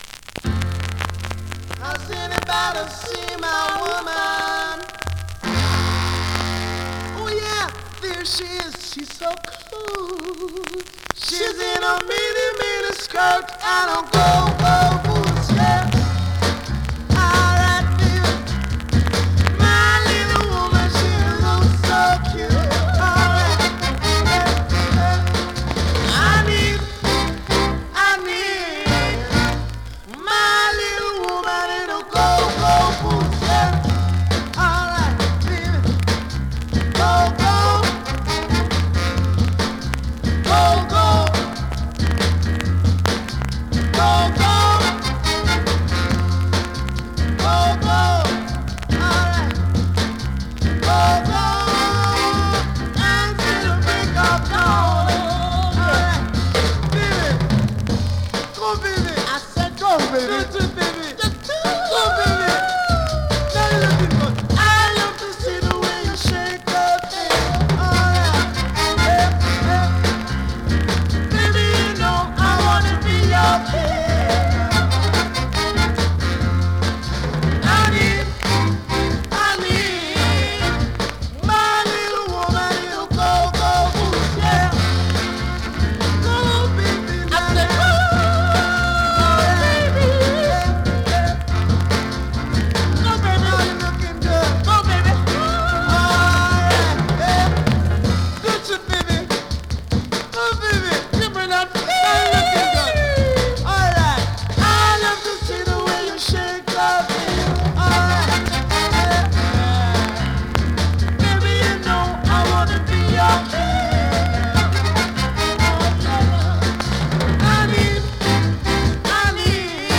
SOUL!!
スリキズ、ノイズそこそこあります。